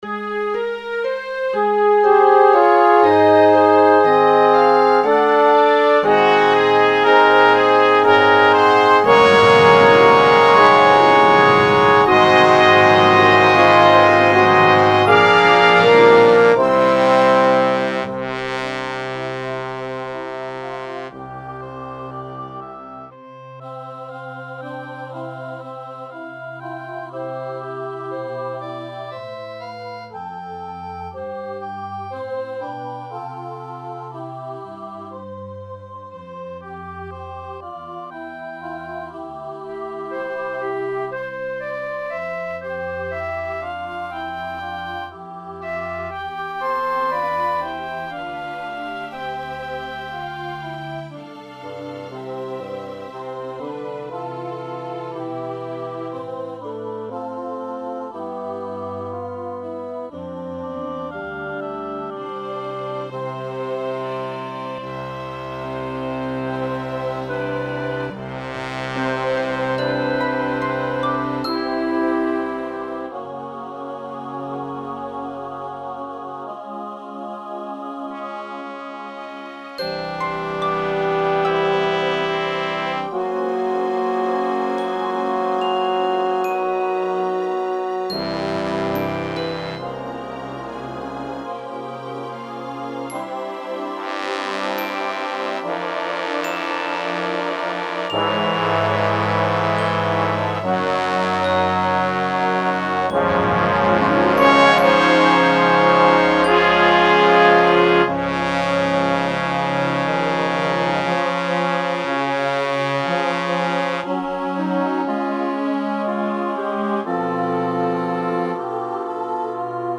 Choral Work